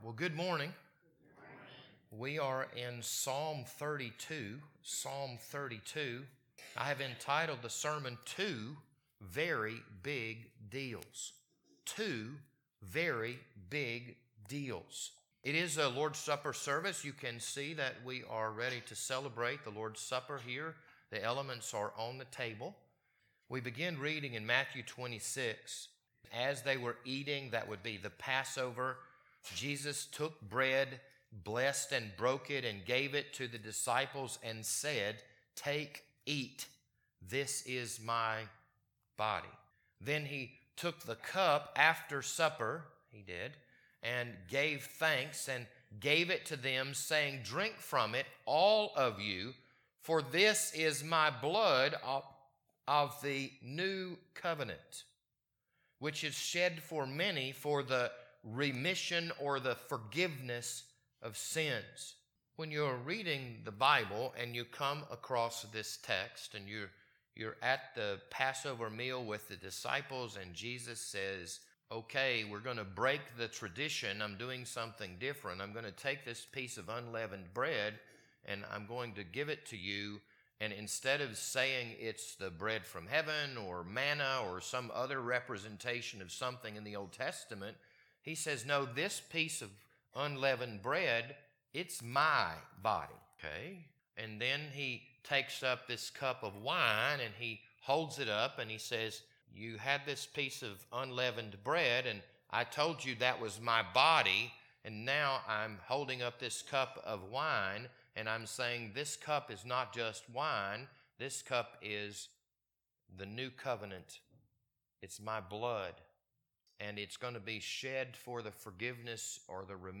This sermon was recorded on June 19th, 2022.